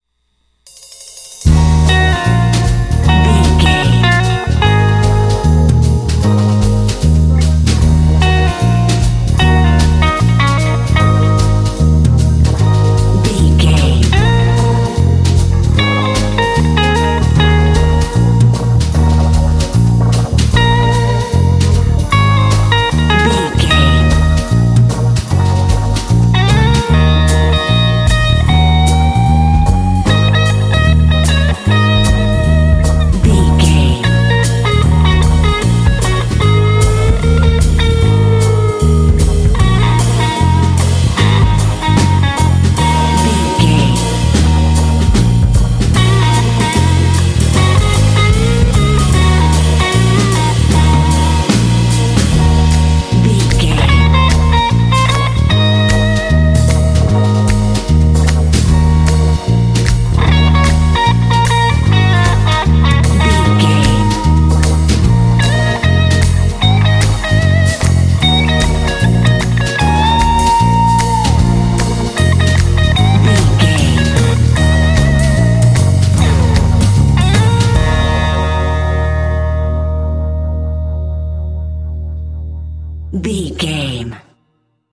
Aeolian/Minor
E♭
dreamy
optimistic
uplifting
bass guitar
drums
electric guitar
synthesiser
jazz
swing